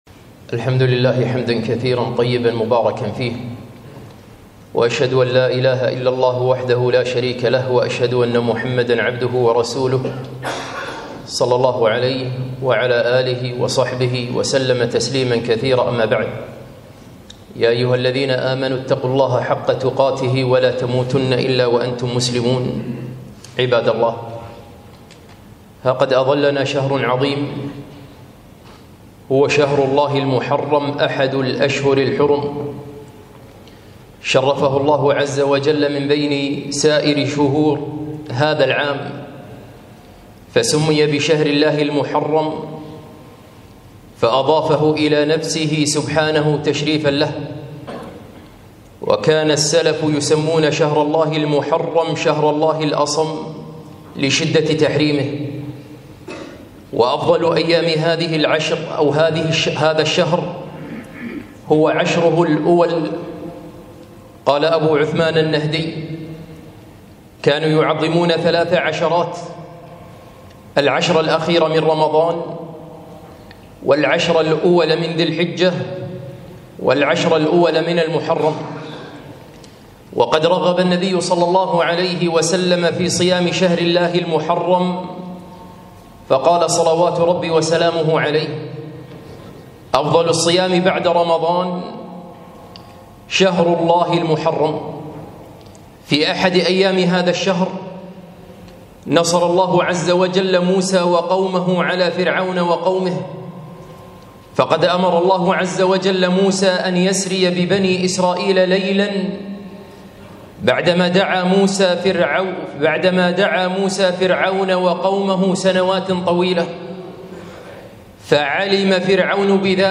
خطبة - شهر الله المحرم وعاشوراء